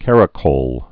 (kărə-kōl) also car·a·col (-kŏl)